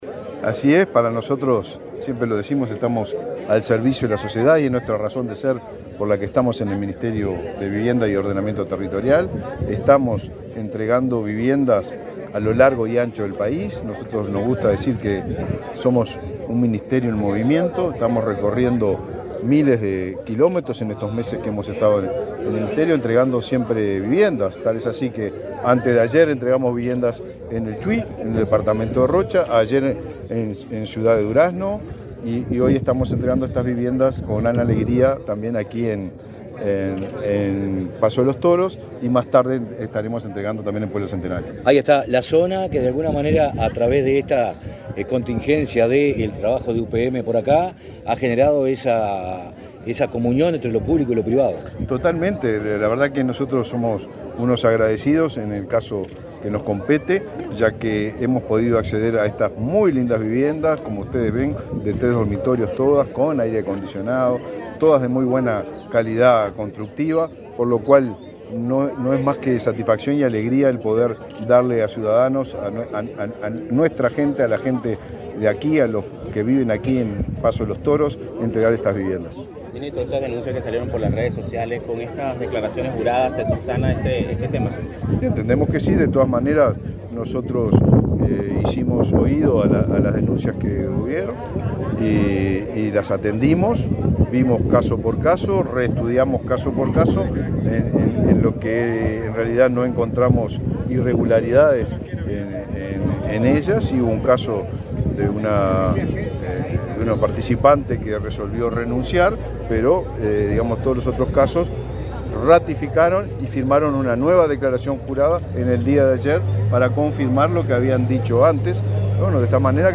Declaraciones a la prensa del ministro de Vivienda, Raúl Lozano, en Tacuarembó
Luego, dialogó con la prensa.